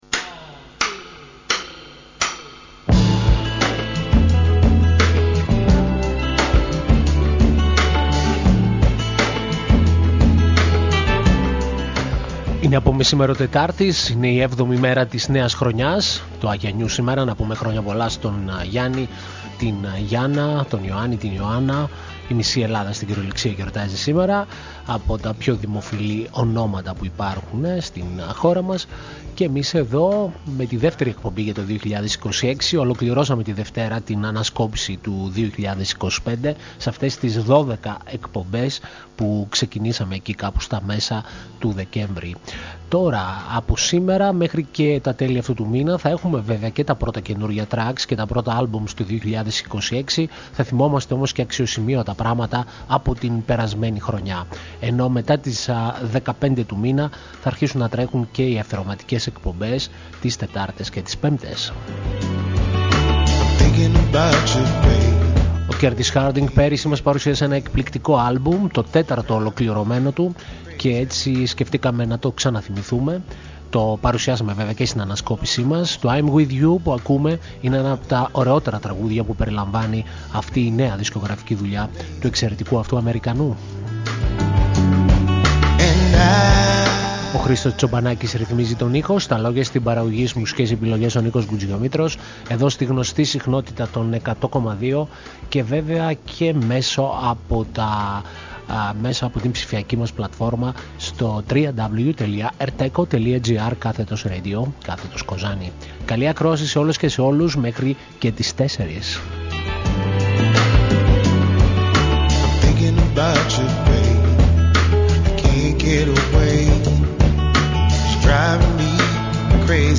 μια επιλογή από τις νέες κυκλοφορίες στη διεθνή δισκογραφία